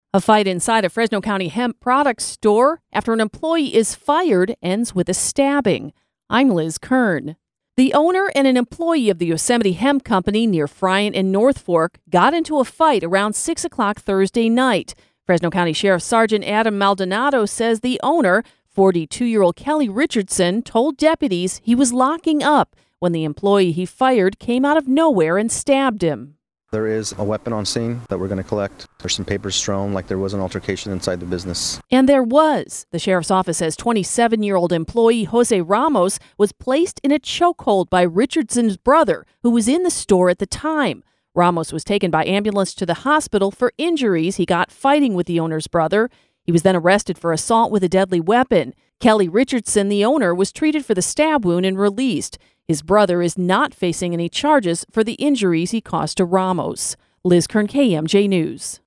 LK-WEB-HEMP-STORE-STABBING.mp3